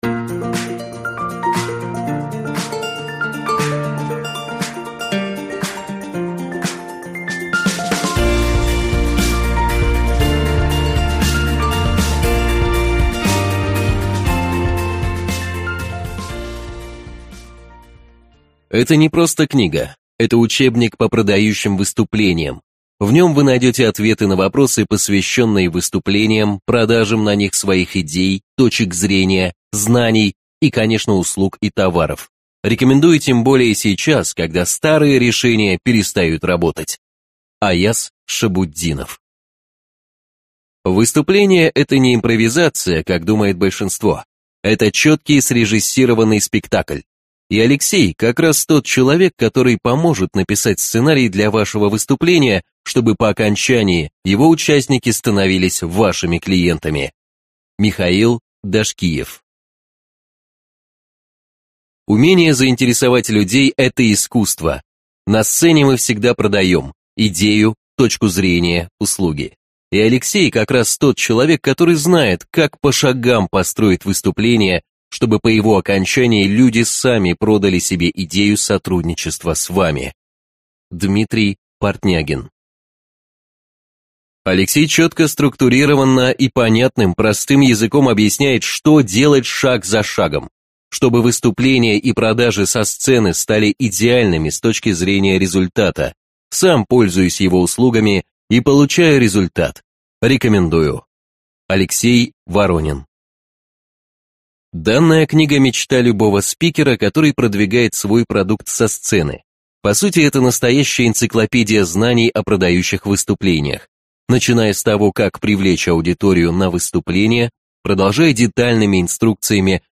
Аудиокнига Большие продажи на вебинарах и выступлениях. Алгоритм успеха для блогеров, предпринимателей, экспертов | Библиотека аудиокниг